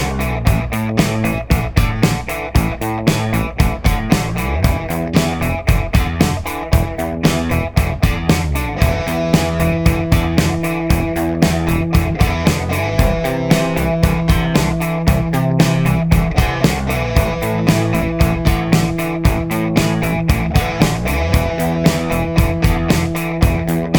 no Backing Vocals Soft Rock 4:17 Buy £1.50